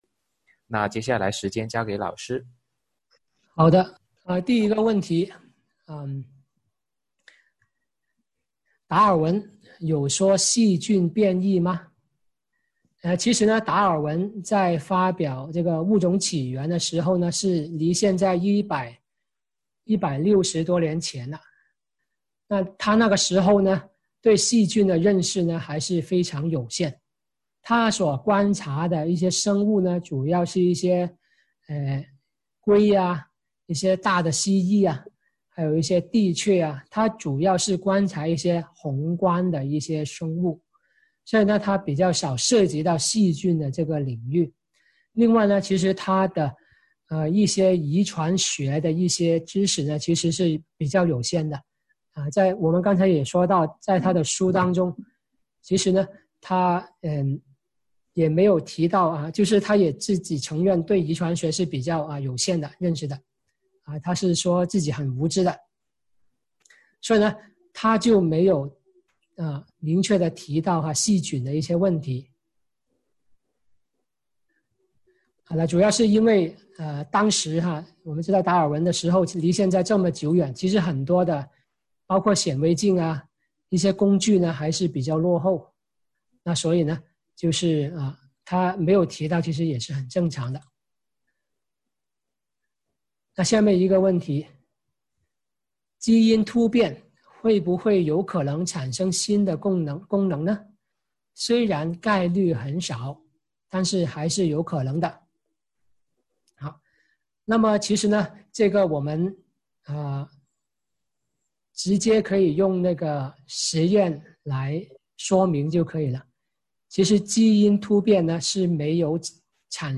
《物种起源》讲座直播回放